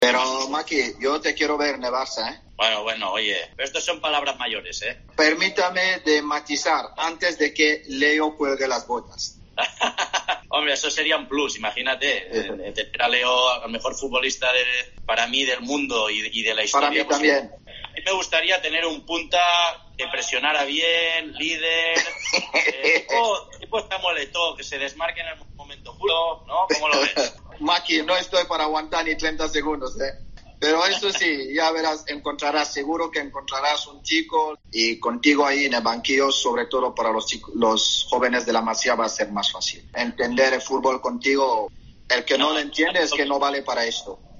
En una conversación telemática, Samuel Eto'o preguntó al de Terrassa por la posibilidad de dirigir al equipo azulgrana antes de que se retire el crack argentino.